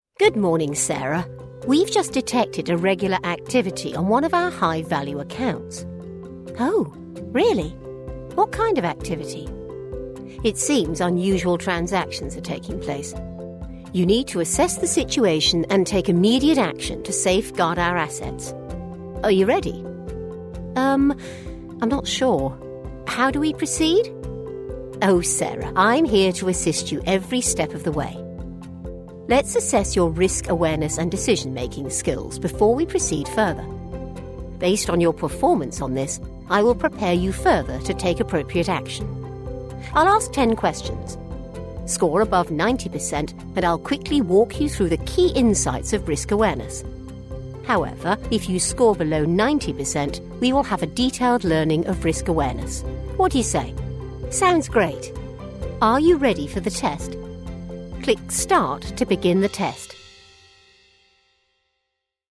An experienced British English voice actor with a warm assured voice and versatility
E learning/ explainer
RP British Northern